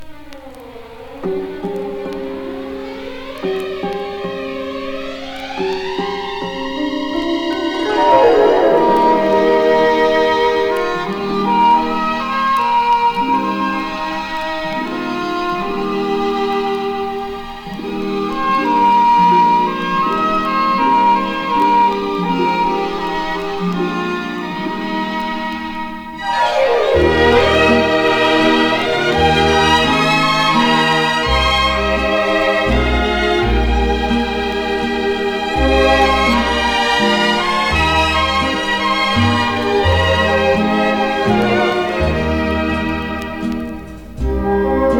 World, Easy Listening, Pops　USA　12inchレコード　33rpm　Mono